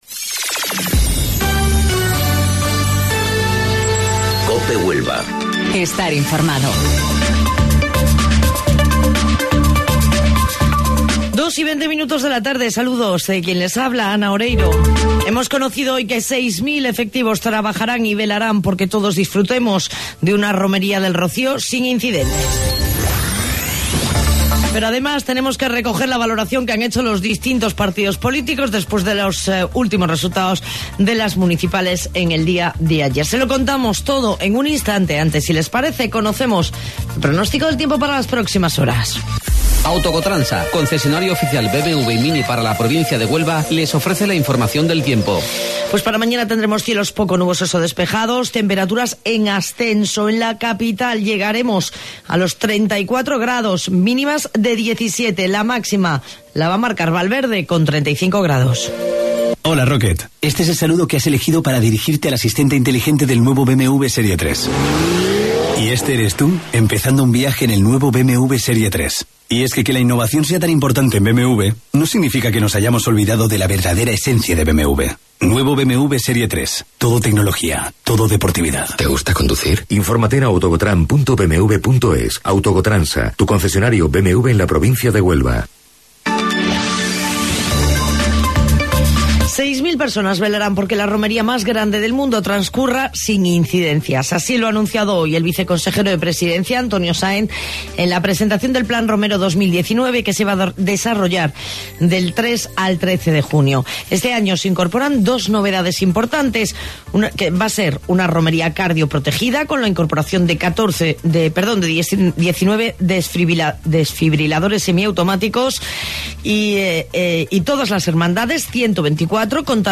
AUDIO: Informativo Local 14:20 del 27 de Mayo